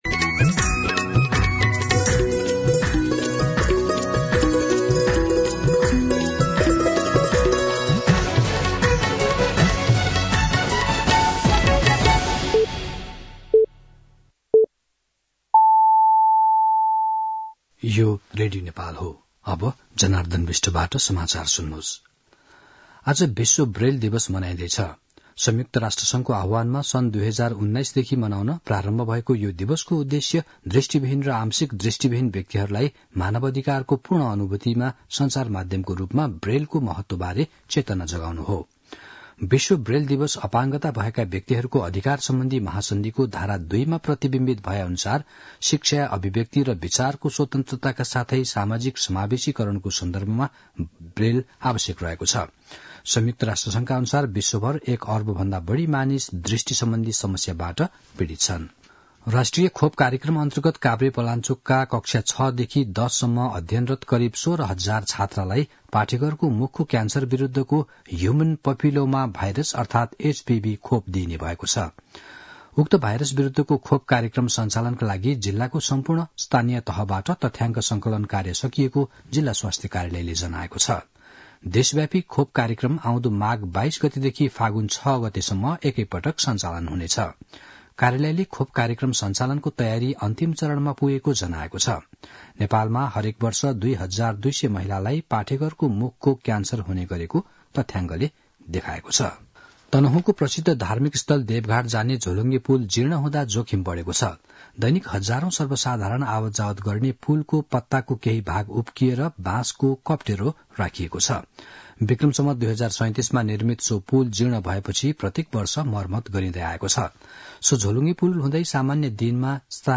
दिउँसो १ बजेको नेपाली समाचार : २१ पुष , २०८१
1-pm-Nepali-News.mp3